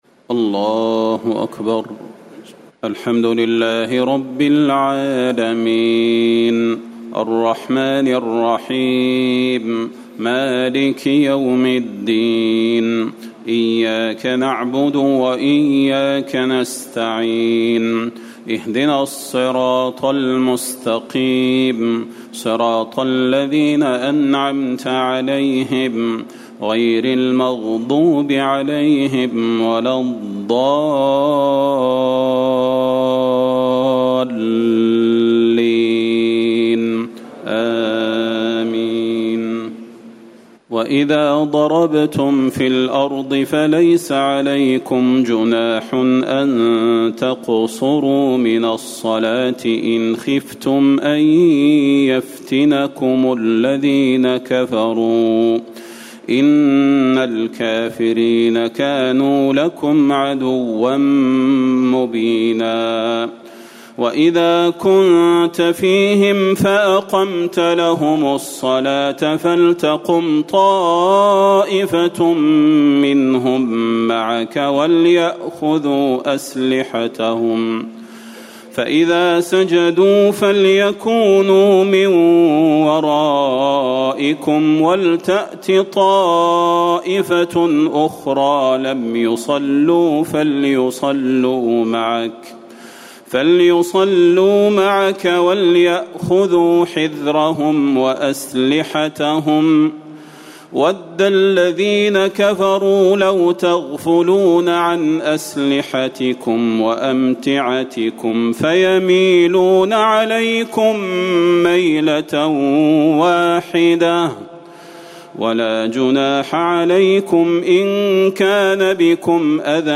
تهجد ليلة 25 رمضان 1439هـ من سورة النساء (101-147) Tahajjud 25 st night Ramadan 1439H from Surah An-Nisaa > تراويح الحرم النبوي عام 1439 🕌 > التراويح - تلاوات الحرمين